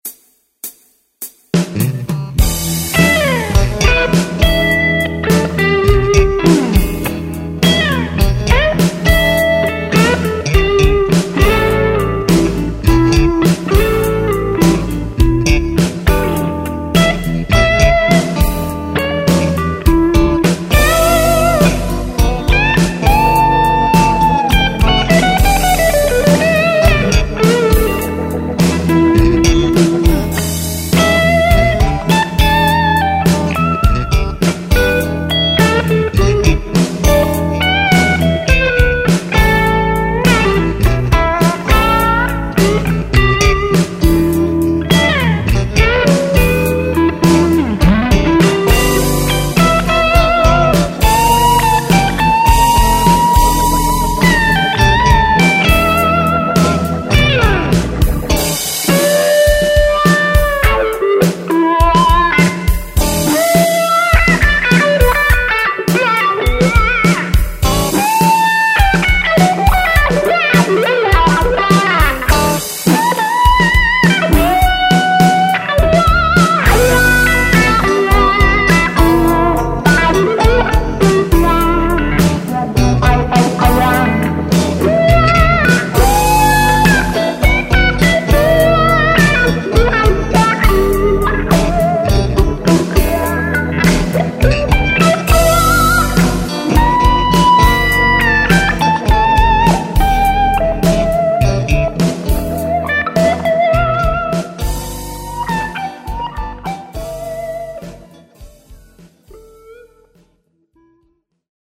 ist ein First Take
Ihr hört meine Thinline Tele mit dem Pod.